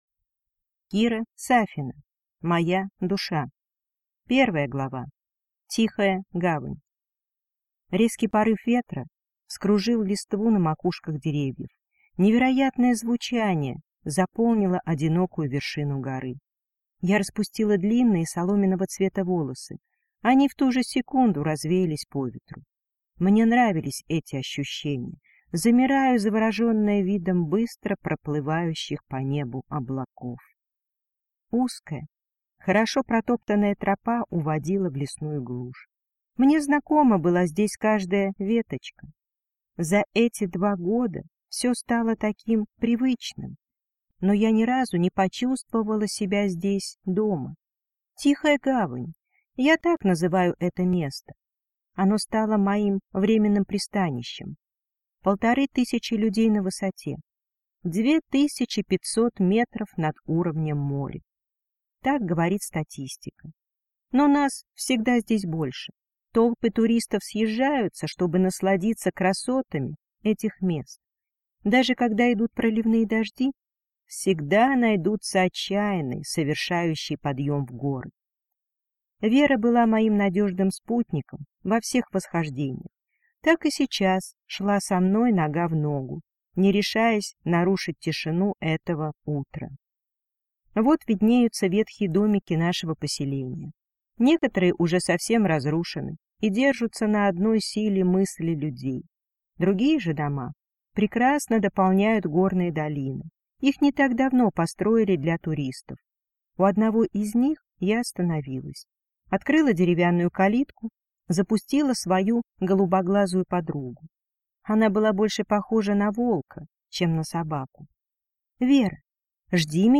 Аудиокнига Моя душа | Библиотека аудиокниг